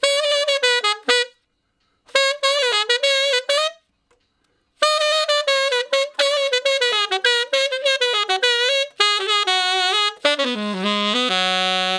068 Ten Sax Straight (Ab) 16.wav